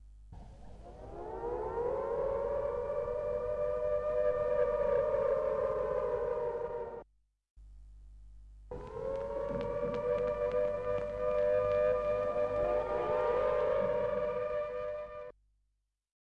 Mellotron二战警报器 - 声音 - 淘声网 - 免费音效素材资源|视频游戏配乐下载
来自Mellotron声音fx卷轴的WW2警报器在Mellotron M400上播放。